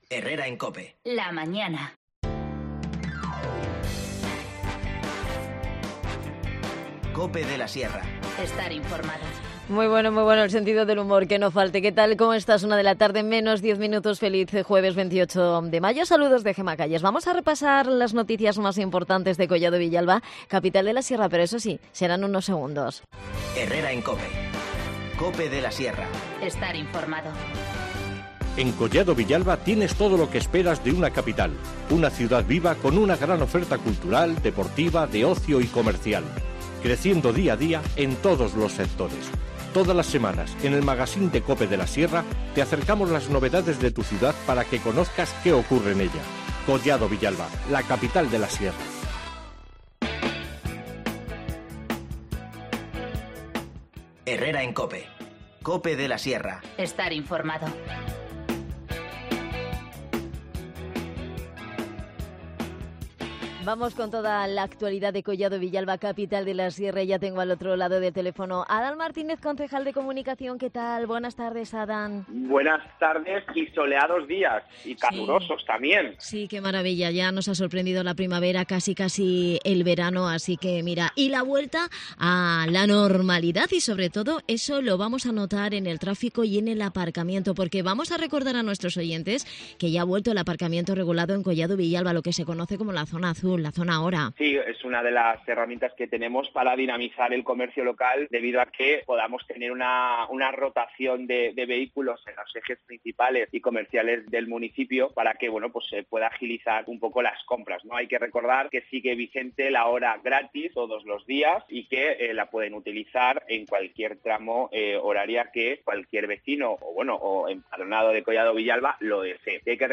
AUDIO: Repasamos con Adan Martínez, concejal de Comunicación de Collado Villalba, los asuntos de actualidad del municipio